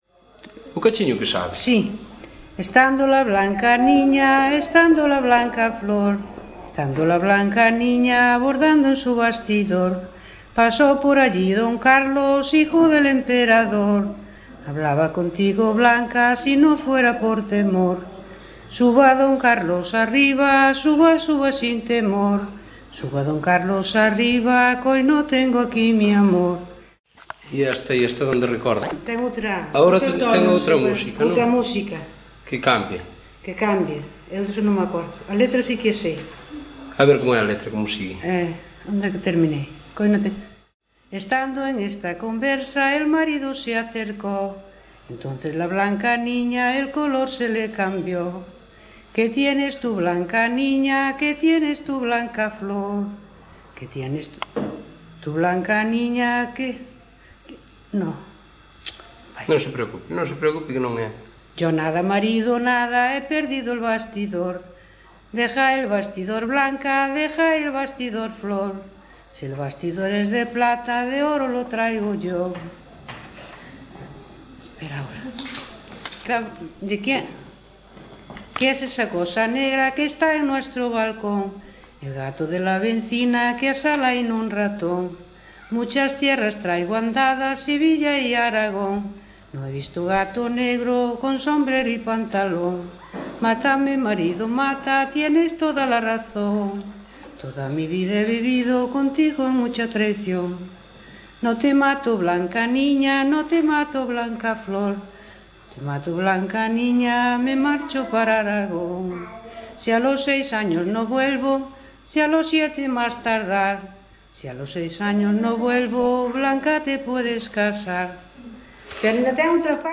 Tipo de rexistro: Musical
Áreas de coñecemento: LITERATURA E DITOS POPULARES > Cantos narrativos > Romances tradicionais
Lugar de compilación: Mesía - Lanzá
Soporte orixinal: Casete
Instrumentación: Voz
Instrumentos: Voz feminina